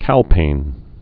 (kălpān)